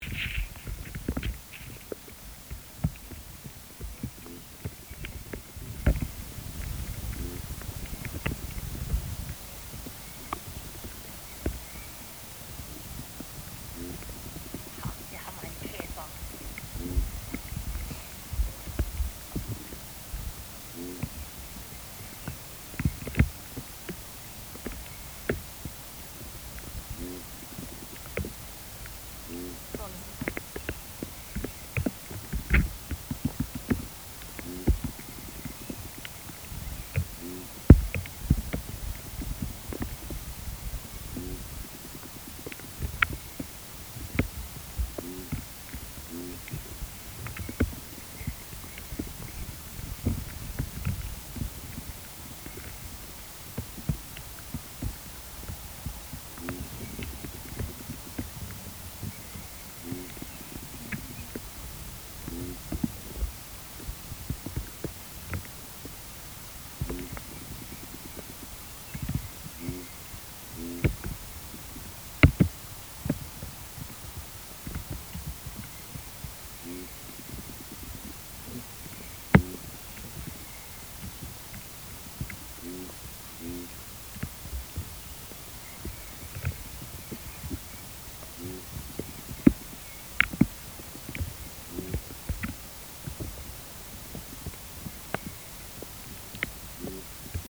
Beim Neue-Musik-Festival "Der Sommer in Stuttgart" im Wald aufgenommene Klänge, zum Anhören bitte anklicken:
ein Käfer.